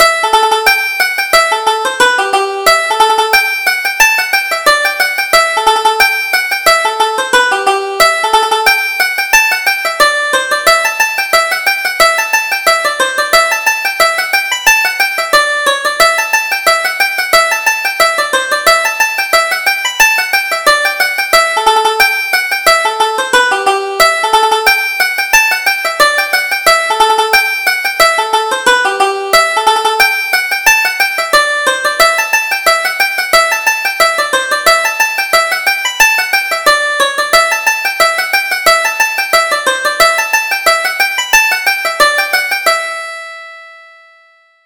Reel: The Merry Harriers